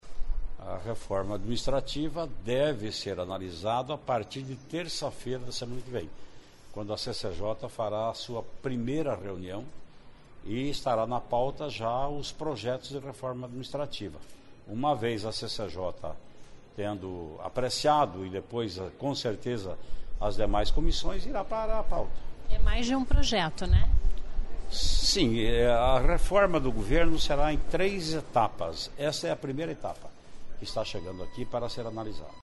Traiano afirmou, durante entrevista coletiva, no começo da tarde desta quarta-feira (13), que projeto que trata da reforma administrativa deve começar a tramitar na próxima semana.